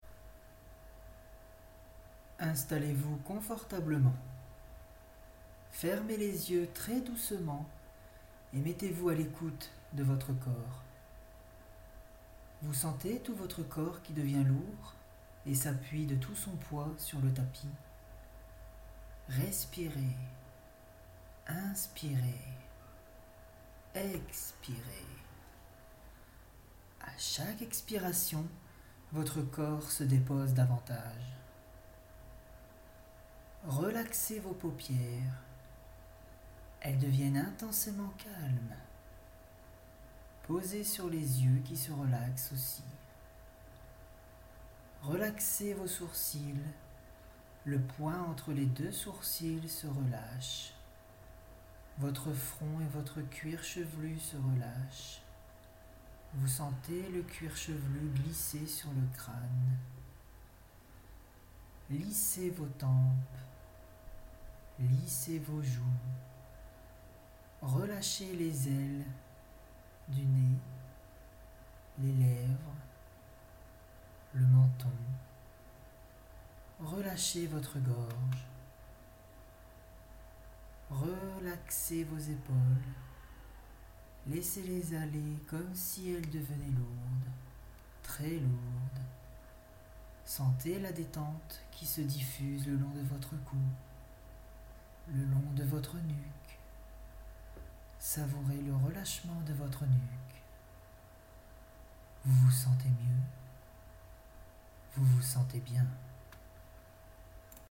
Voix off
Voix Relax